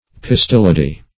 Search Result for " pistillody" : The Collaborative International Dictionary of English v.0.48: Pistillody \Pis"til*lo*dy\, n. [Pistil + Gr. e'i^dos form.]